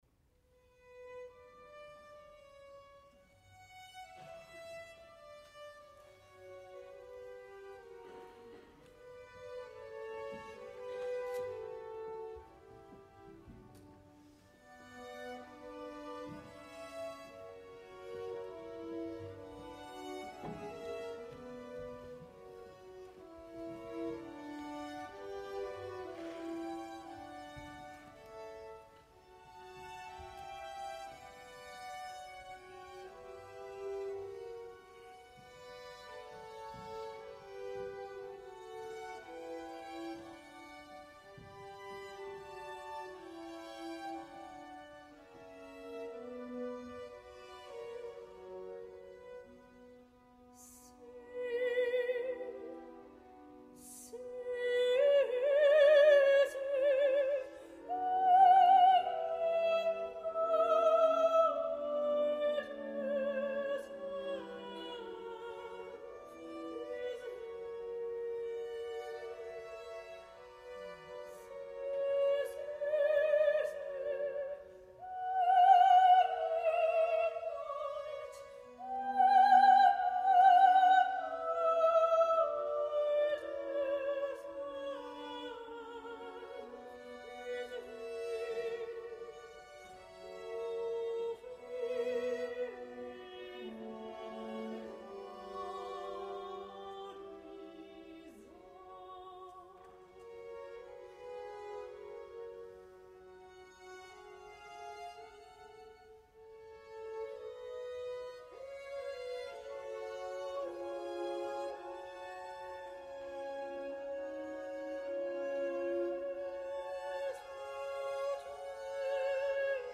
en una versió semi-escenificada
la transmissió radiofònica